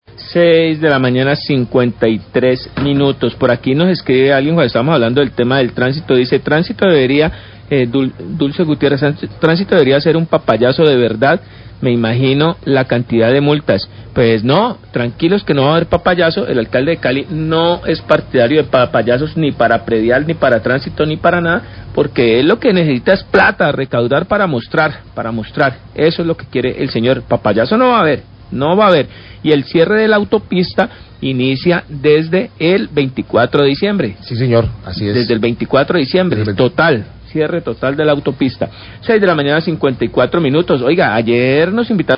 Radio
'El alcalde de Cali no se presta para esas cosas' locutores de MaxiNoticias tras leer comentario de oyente relacionado a informe de plazo extendido para pago de multas por parte de la secretaría de movilidad.